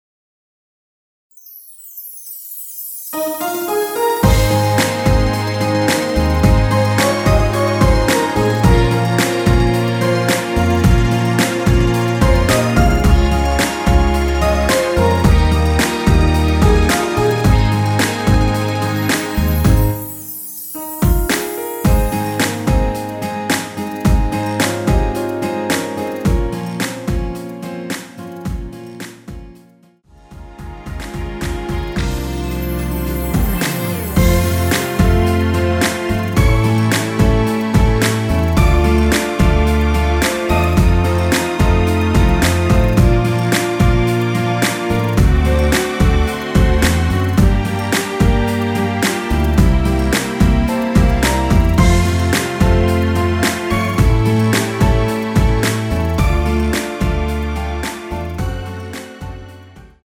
전주가 너무길어 시작 Solo 부분22초 정도 없이 제작 하였으며
엔딩부분이 페이드 아웃이라 엔딩을 만들어 놓았습니다.(원키 미리듣기 참조)
Ab
앞부분30초, 뒷부분30초씩 편집해서 올려 드리고 있습니다.
중간에 음이 끈어지고 다시 나오는 이유는